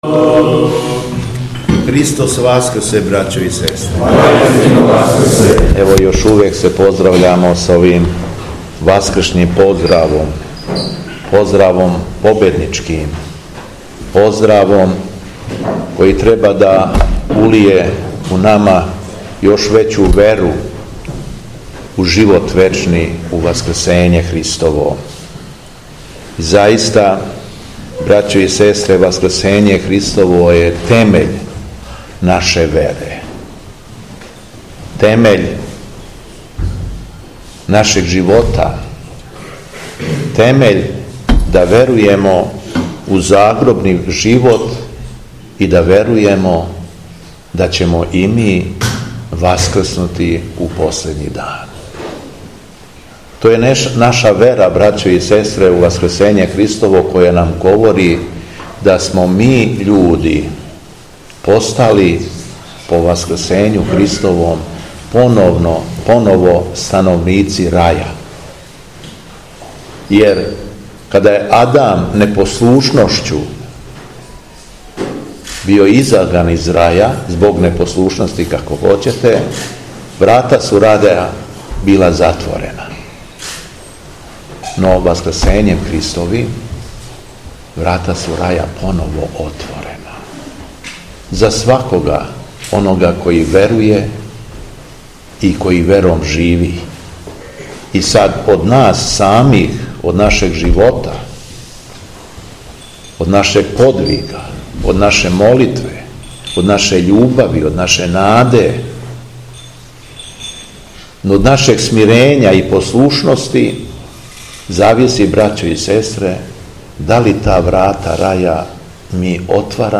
У понедељак, шесте недеље по Пасхи, када наша Света, Саборна и Апостолска Црква празнује молитвени спомен на пренос моштију Светог оца Николаја, епископа и чудотворца Мирликијског, манастир Драча литургијски је прославио своју крсну славу.
Беседа Његовог Преосвештенства Епископа шумадијског г. Јована